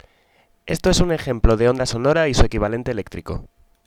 EJEMPLO DE SEÑAL DE VOZ Y SU EQUIVALENTE ELÉCTRICO
En este archivo sonoro tenemos un ejemplo de una señal de voz.
ejemplo_voz.wav